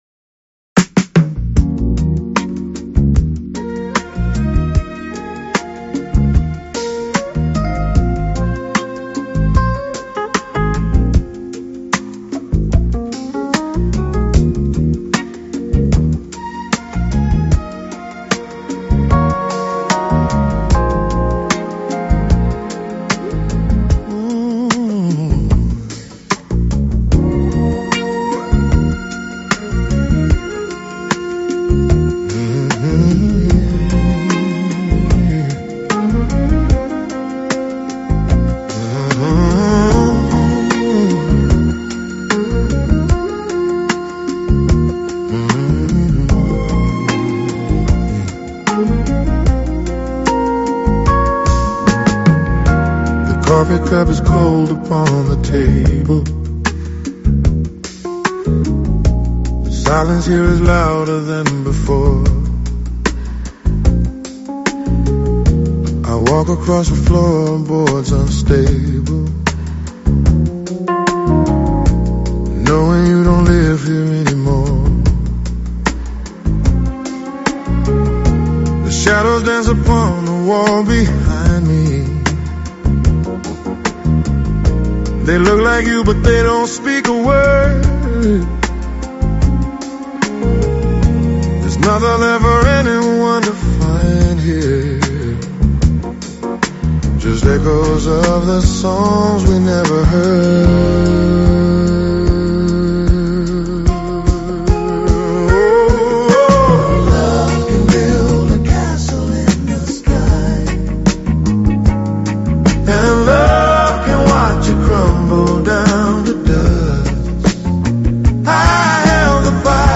Rhythm and Soul